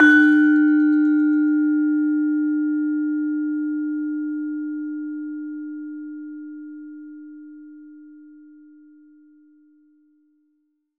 LAMEL D3  -R.wav